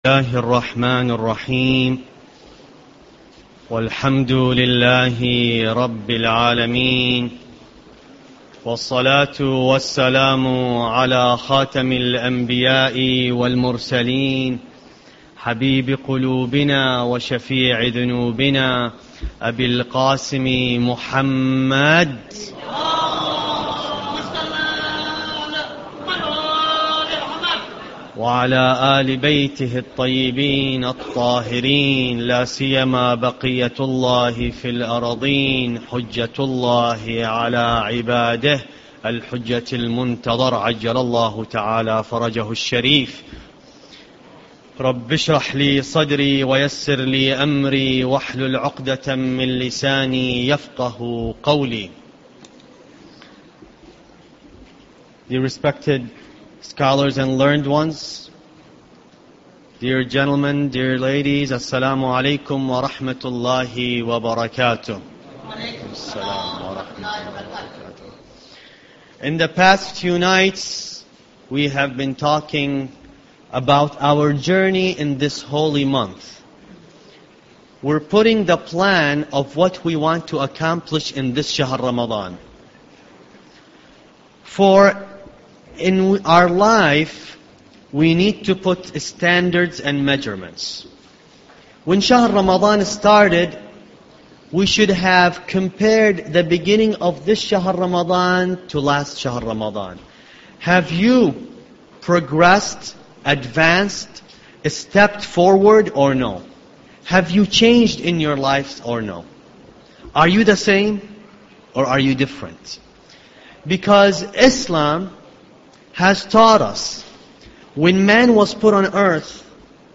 Ramadan Lecture 4